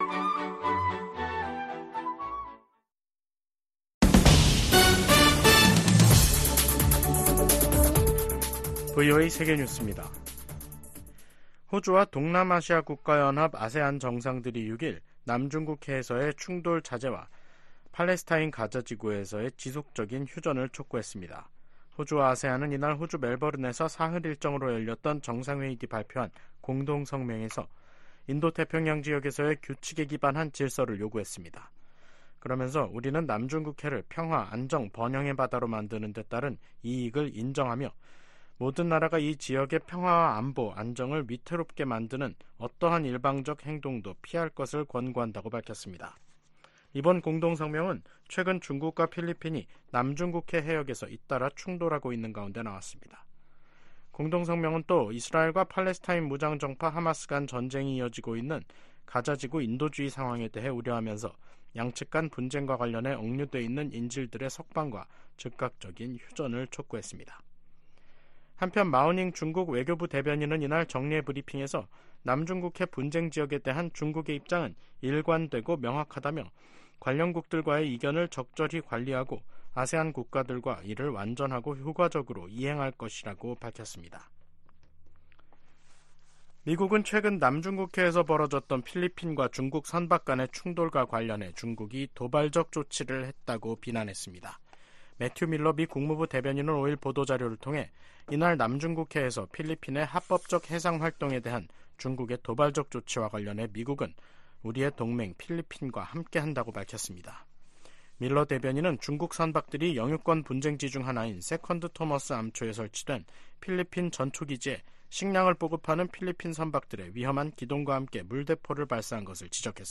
VOA 한국어 간판 뉴스 프로그램 '뉴스 투데이', 2024년 3월 6일 3부 방송입니다. 미 국무부 고위 관리가 북한 비핵화에 중간 단계 조치 필요성을 인정했습니다.